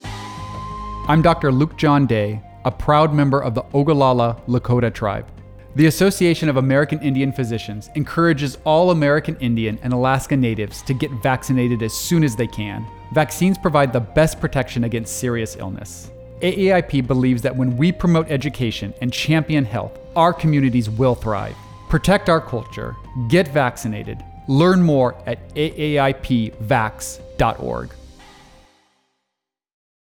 Radio / Televsion PSAs